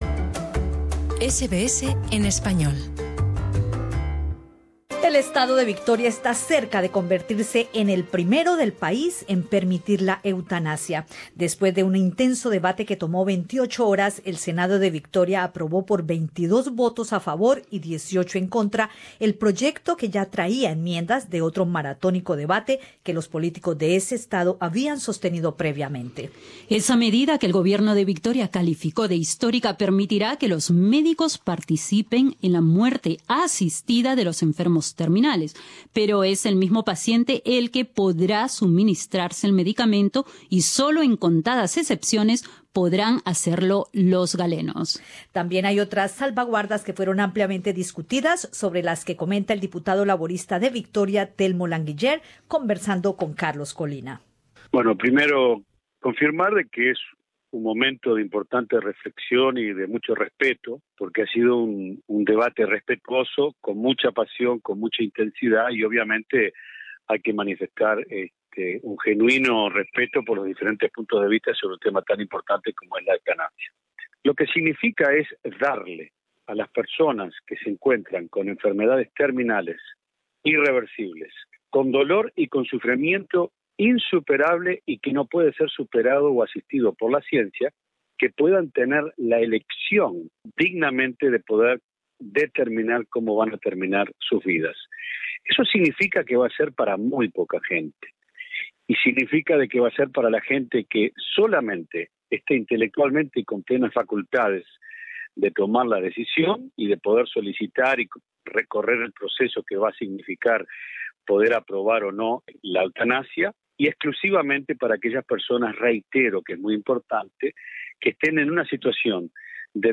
El diputado Telmo Languiller, del Parlamento de Victoria, explica en qué consiste el plan de permitir la eutanasia y cuáles son los límites